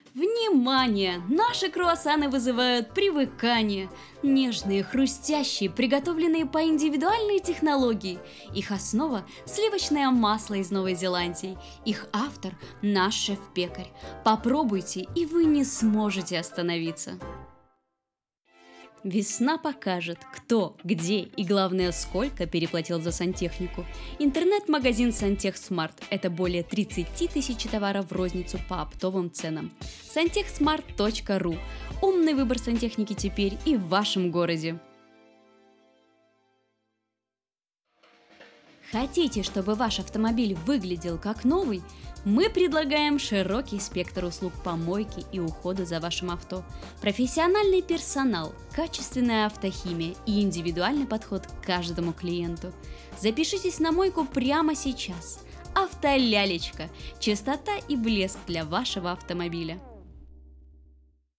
Голос меняю по щелчку пальца, от самого серьезного до голоса Чебурашки